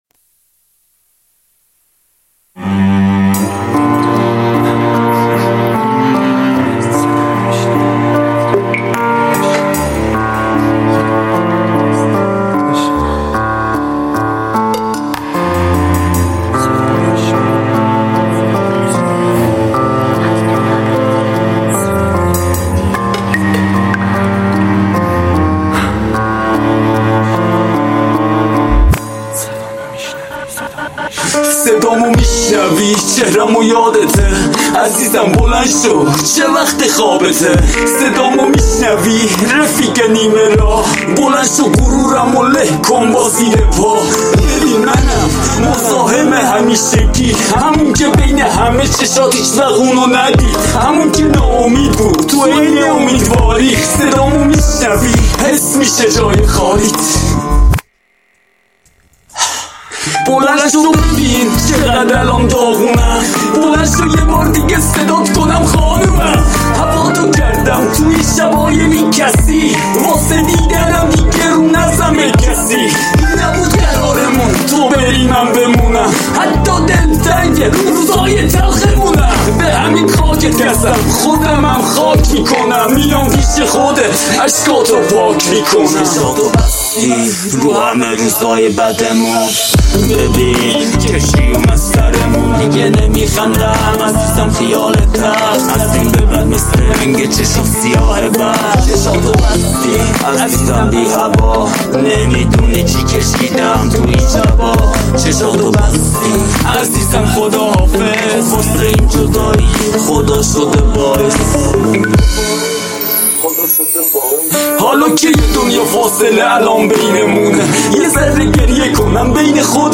rap & hip hop Group